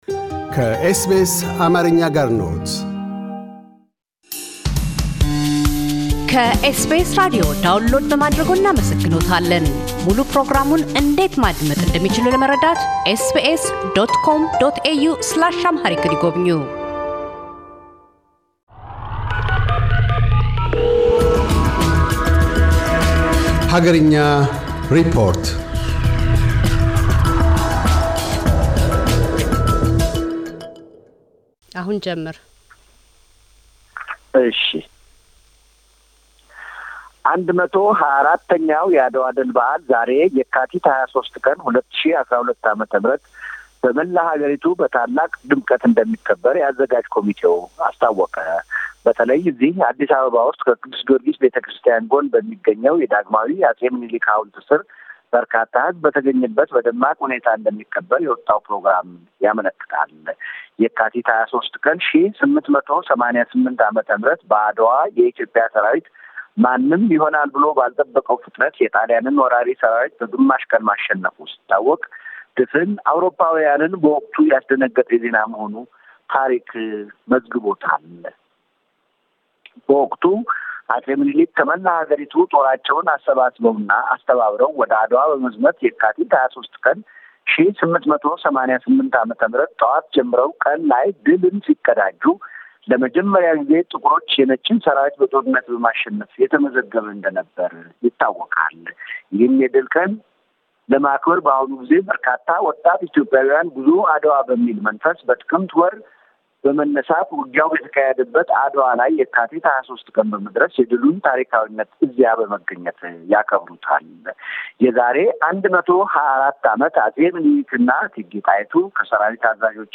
አገርኛ ሪፖርት